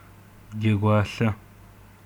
Adyghe Shapsug гьэгуалъэ/gägwaĺa
[ɡʲaɡʷaːɬa] 'toy' Dialectal. Corresponds to [d͡ʒ] in other dialects.